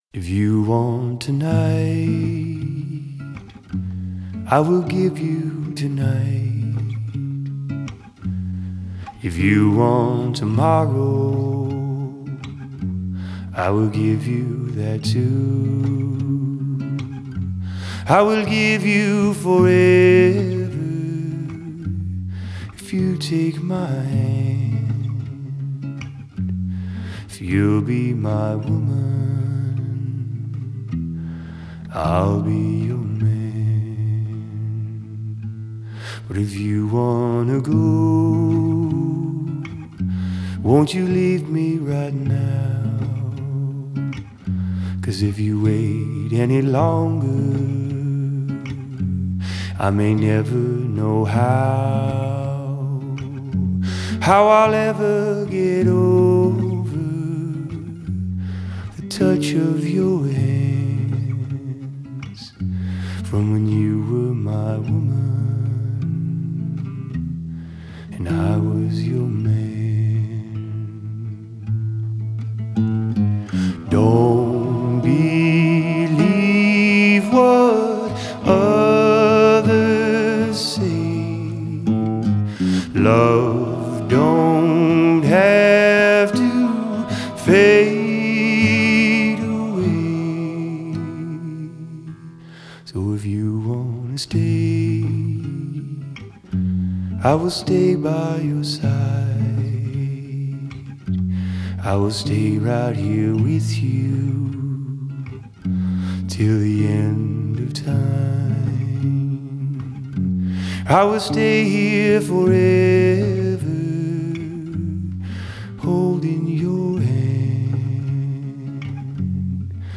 subtle and understated performance